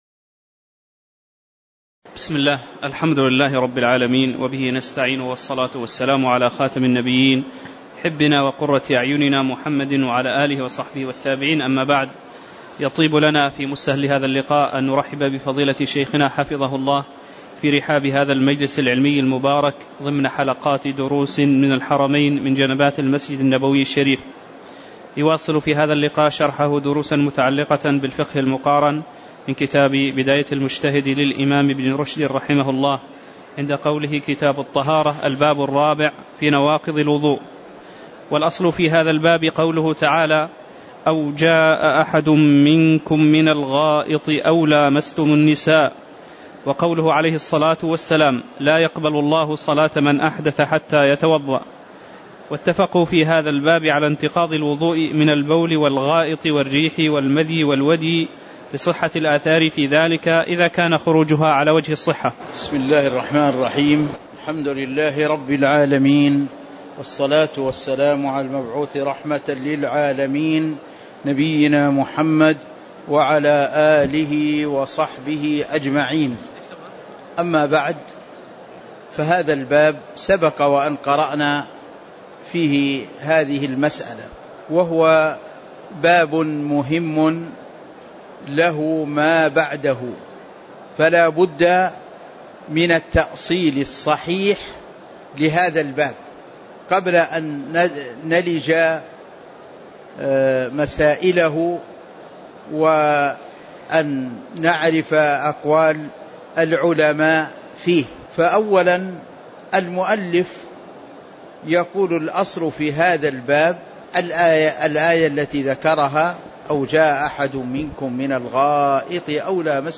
تاريخ النشر ٢٠ صفر ١٤٤٠ هـ المكان: المسجد النبوي الشيخ